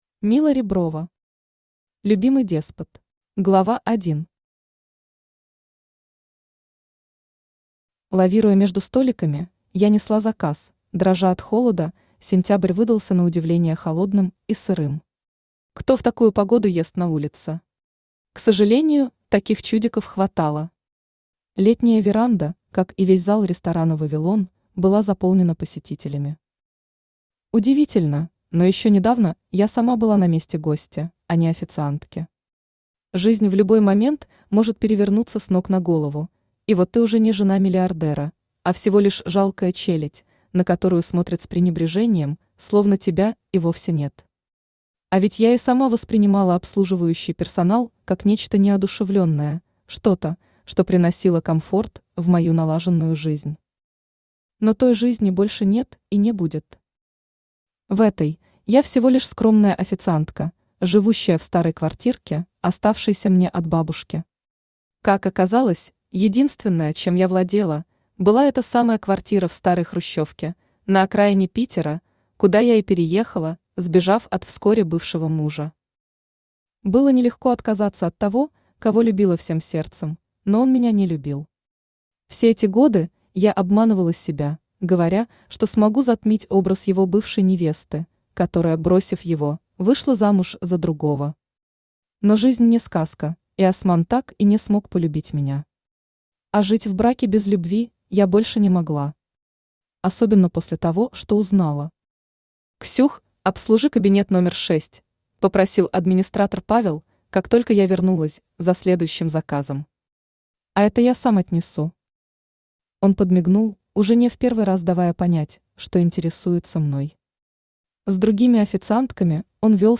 Аудиокнига Любимый деспот | Библиотека аудиокниг
Aудиокнига Любимый деспот Автор Мила Александровна Реброва Читает аудиокнигу Авточтец ЛитРес.